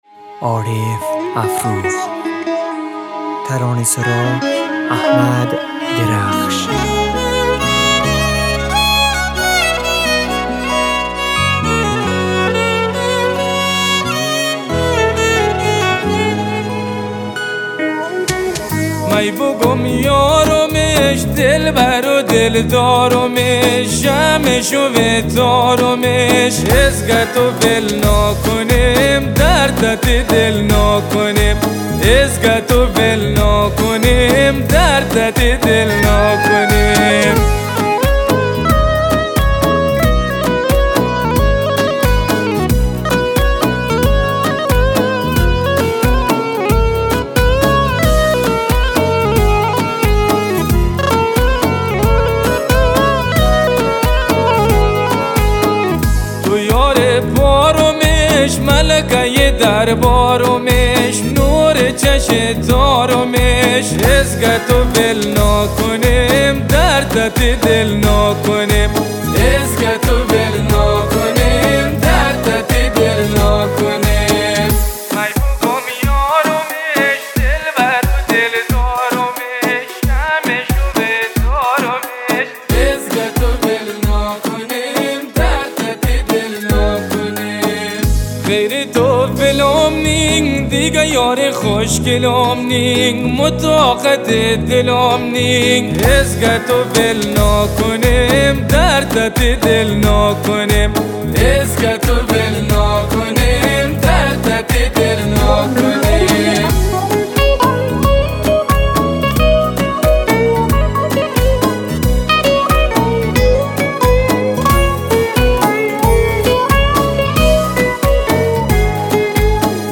بستکی
عالییییییییییییییییی خوش صدا